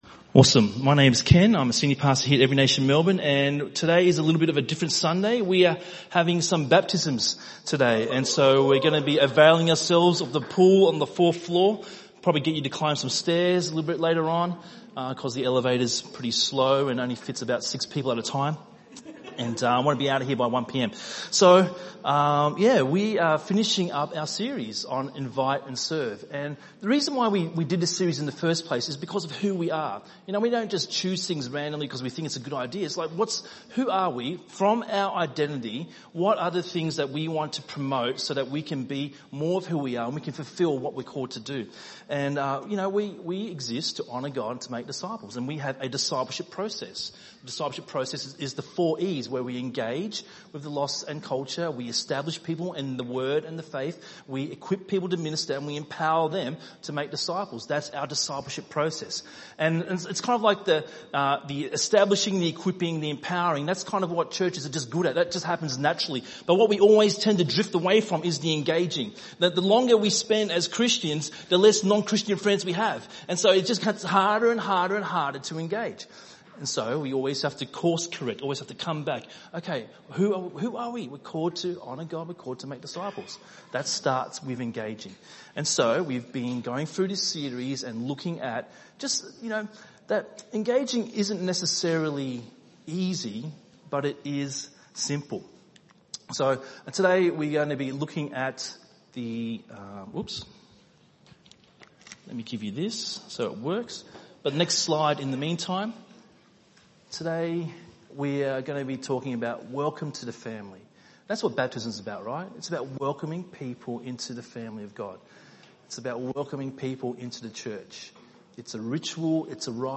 ENM Sermon